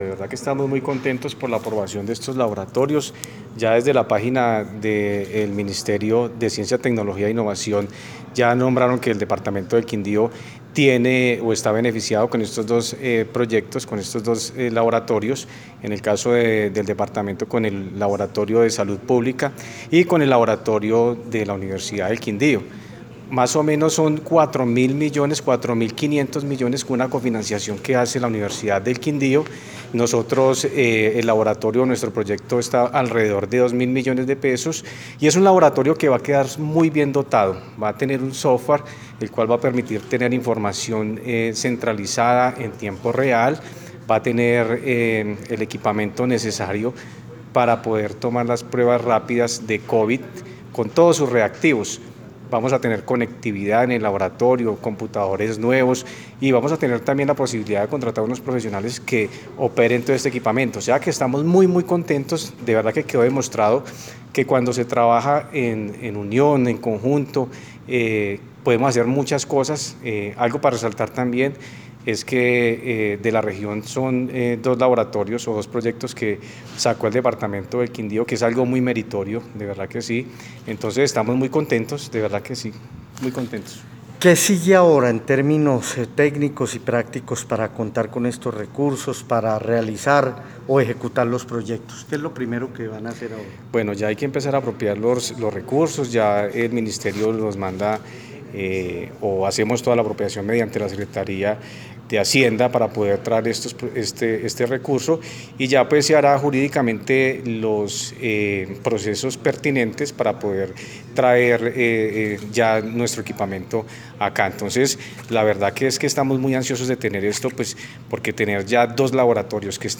Voz, John Mario Liévano Fernández, Secretario de la TIC